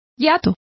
Complete with pronunciation of the translation of hiatus.